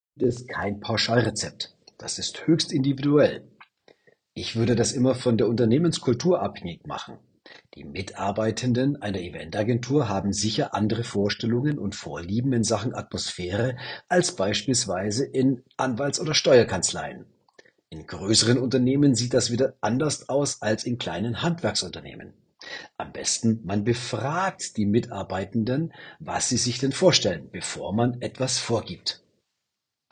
Er ist ehemaliger Leistungssportler, Mental- und Kommunikationscoach.